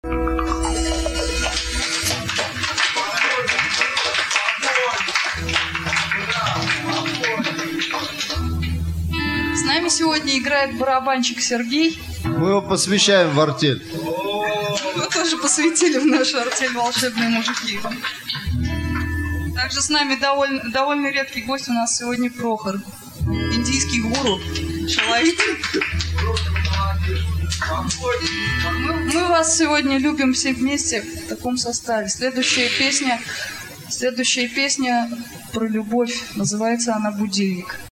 Концерт 14 февраля